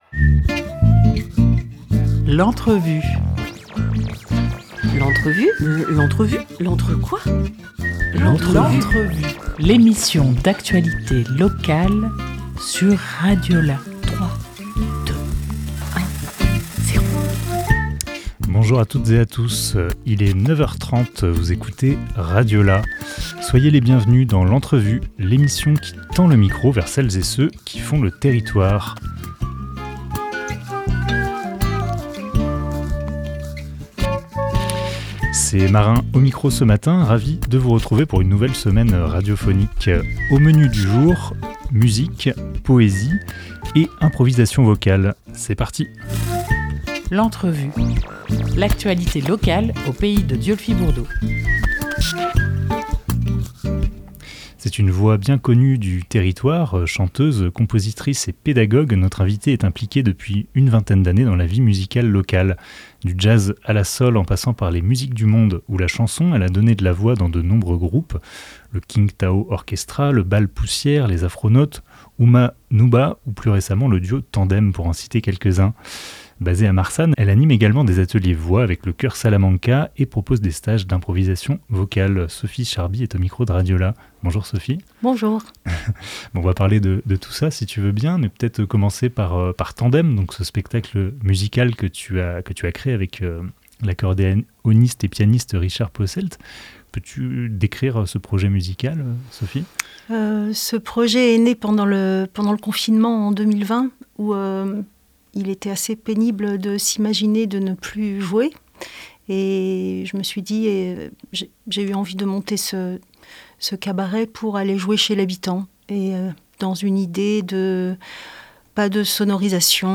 6 février 2024 11:14 | Interview